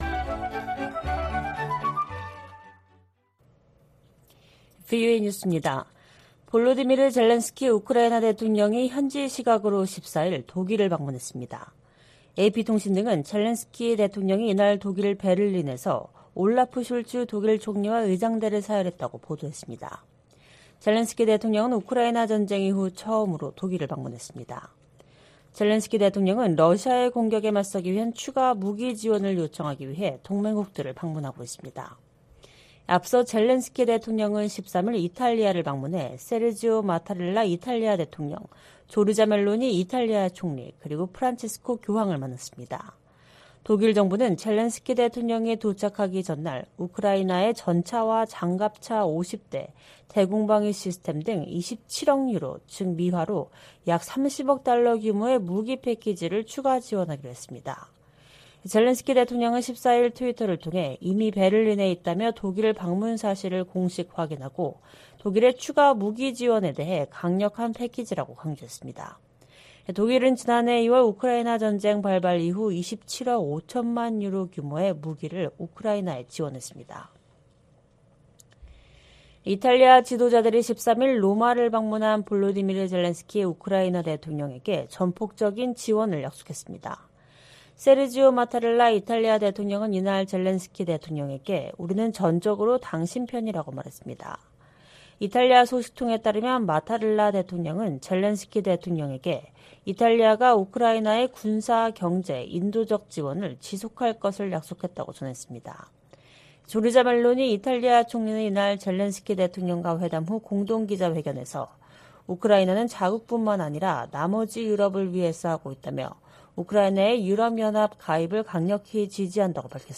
VOA 한국어 방송의 일요일 오후 프로그램 4부입니다.